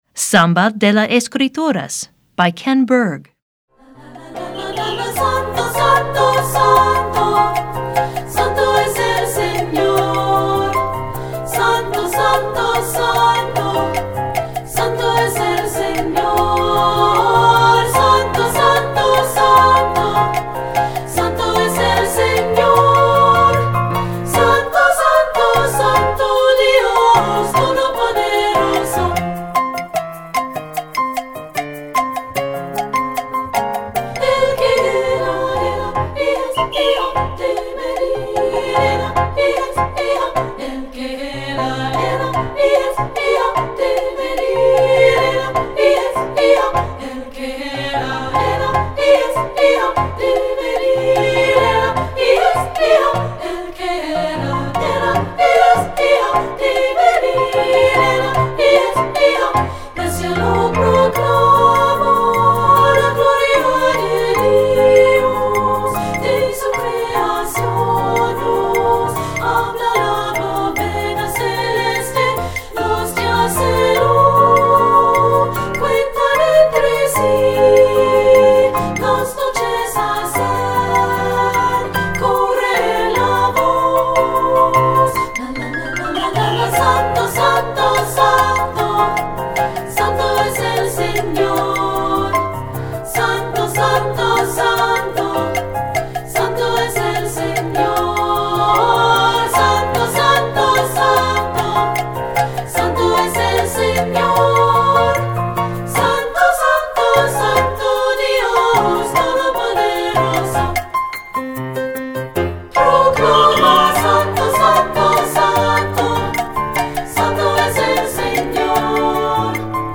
Voicing: 3-Part Treble